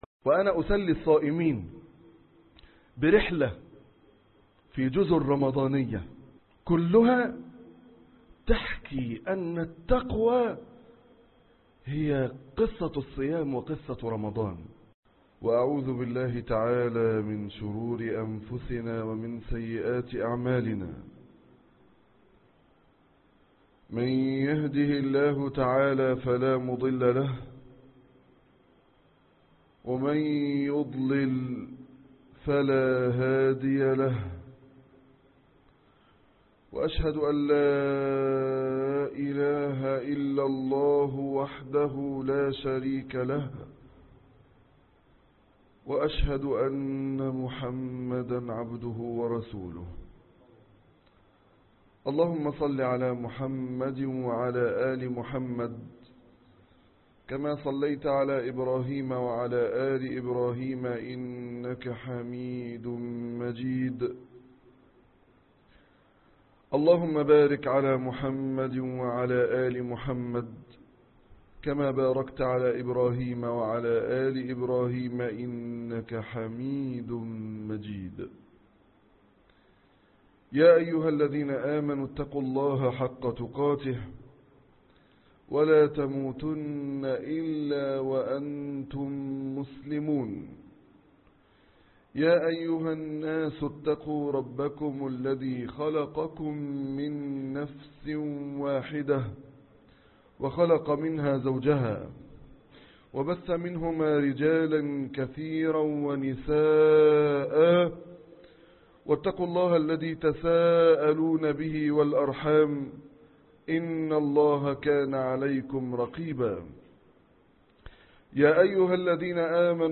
نزهة رمضانية -خطب الجمعة